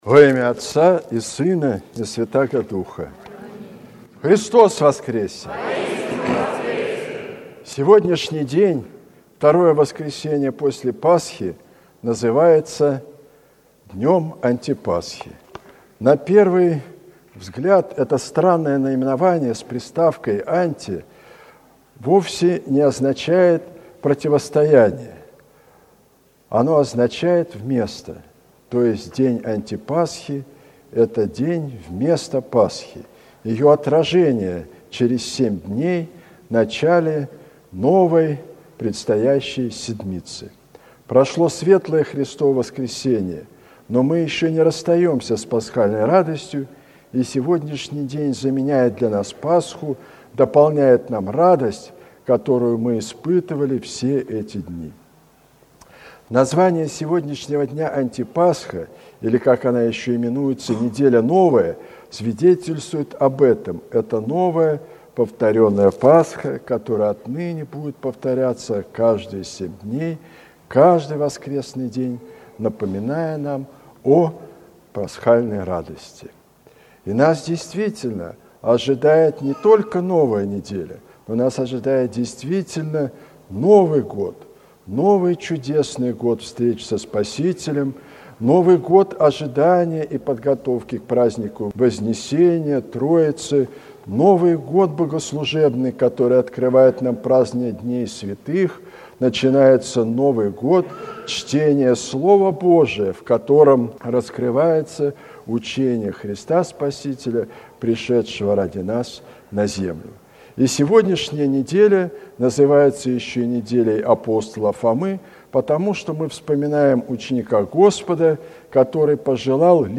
Слово в Неделю Антипасхи